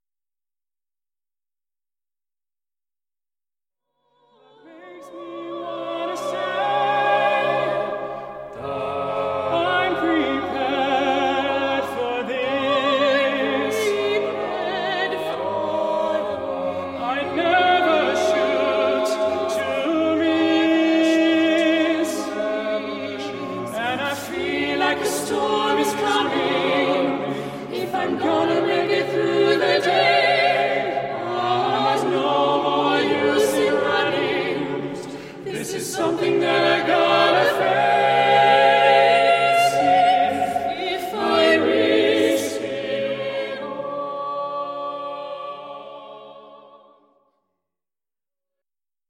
A Cappella Band